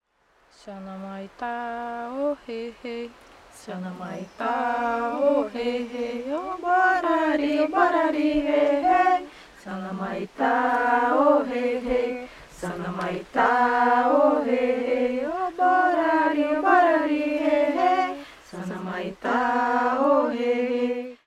Mundurukú & Borari People